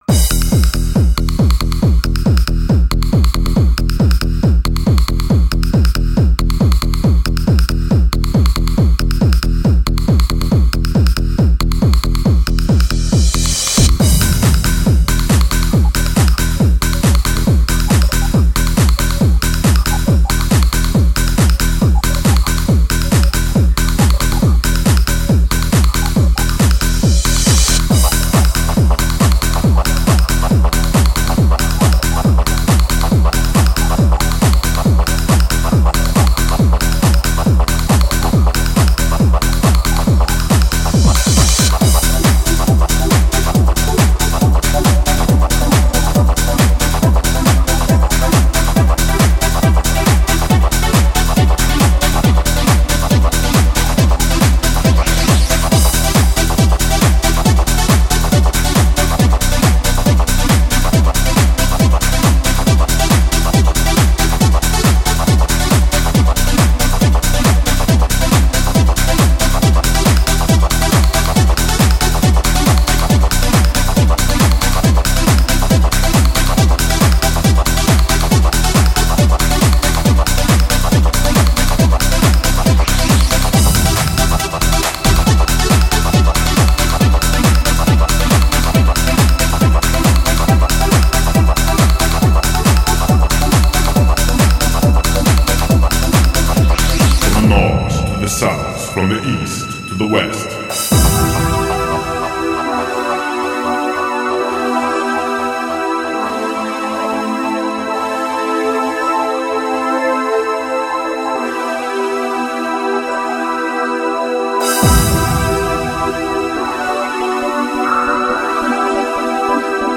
Жанр: Dream, Trance